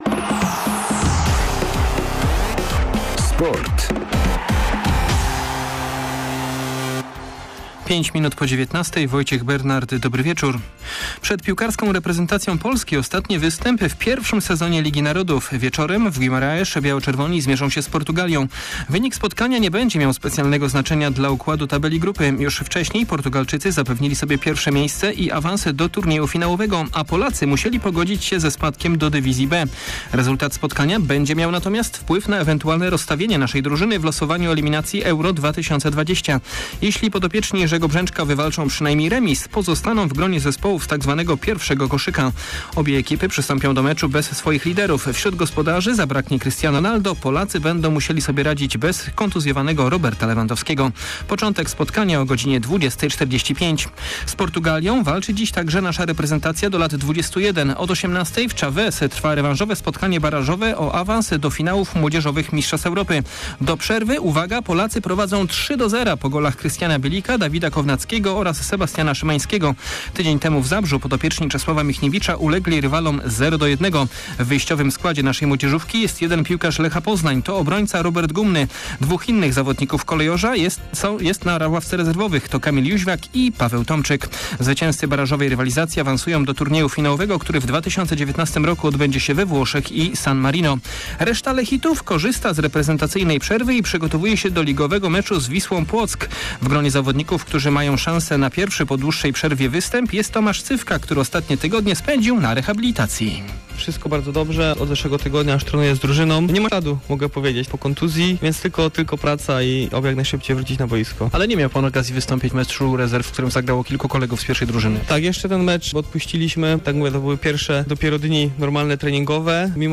20.11. SERWIS SPORTOWY GODZ. 19:05